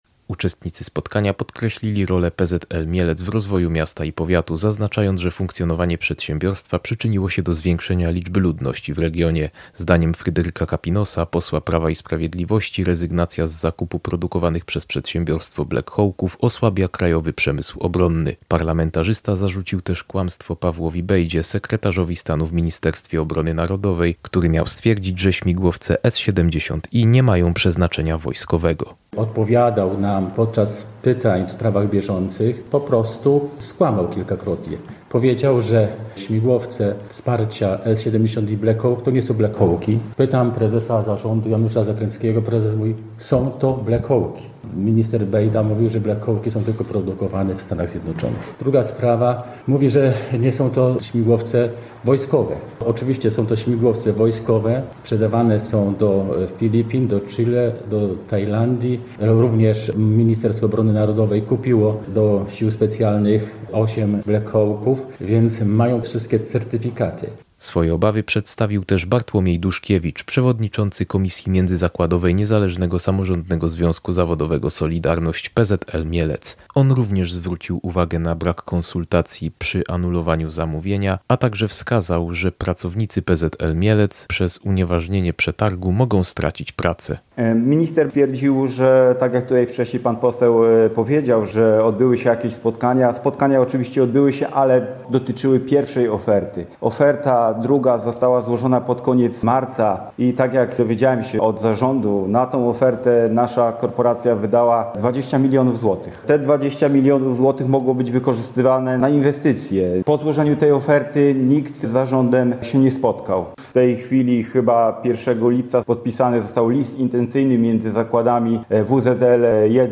Związkowcy z mieleckich zakładów lotniczych i politycy PiS domagają się wznowienia postępowania na zakup śmigłowców S70i Black Hawk. Podczas konferencji w Mielcu przekonywali, że brak zamówień osłabia krajowy przemysł obronny.
– mówił poseł PiS Fryderyk Kapinos.